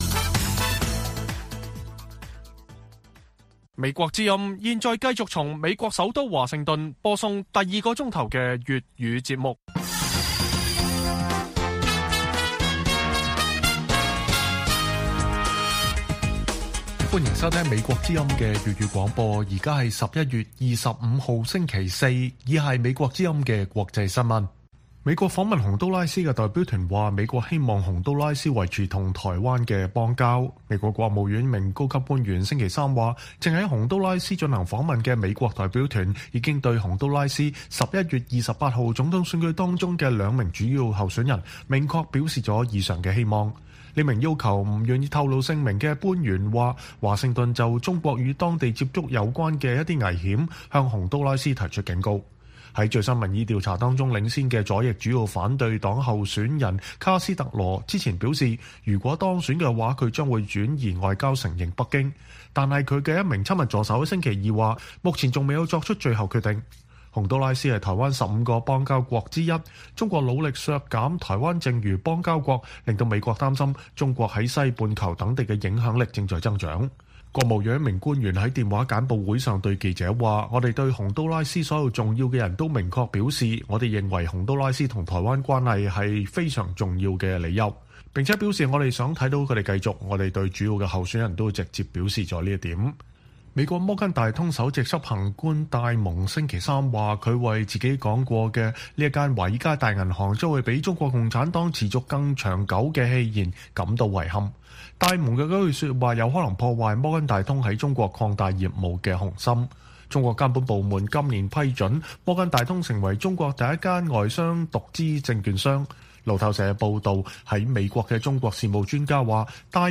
粵語新聞 晚上10-11點: 美國希望洪都拉斯維持與台灣的邦交